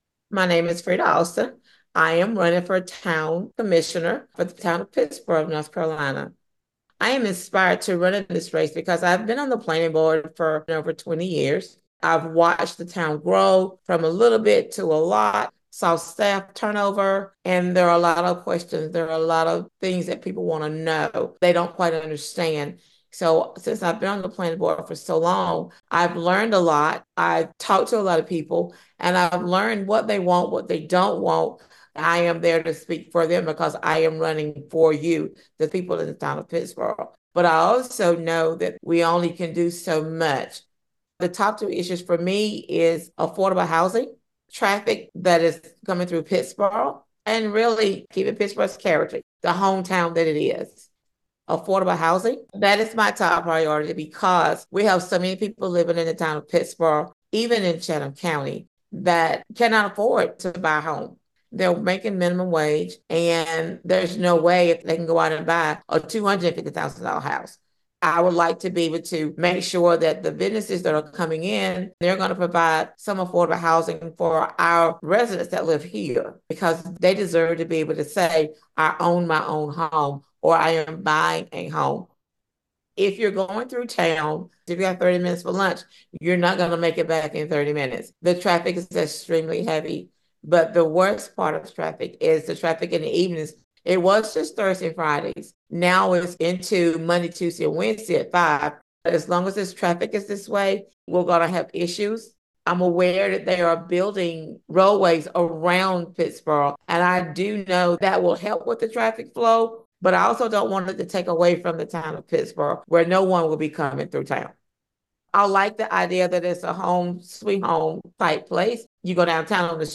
97.9 The Hill spoke with candidates, asking these questions that are reflected in the recorded responses: